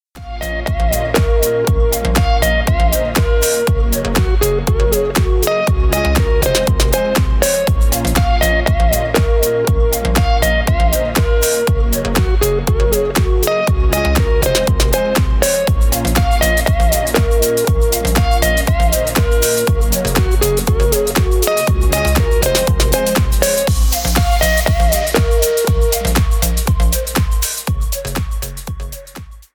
Танцевальные
без слов